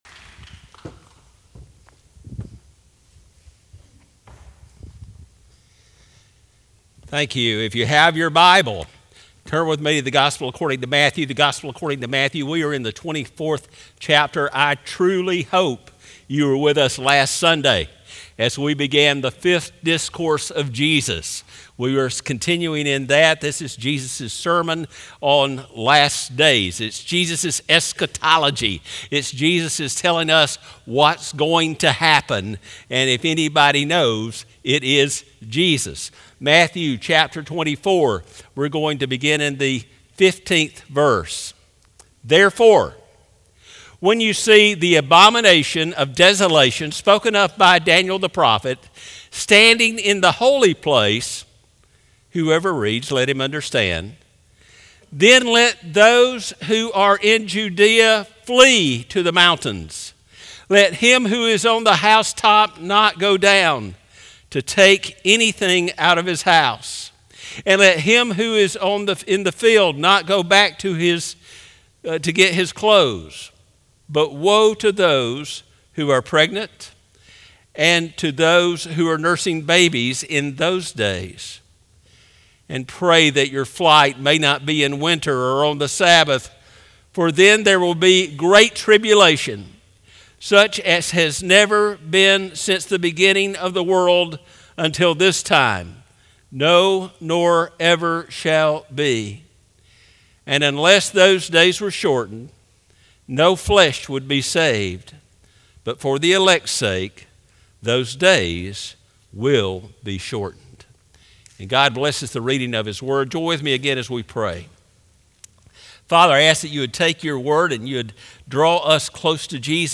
Sermons - Northside Baptist Church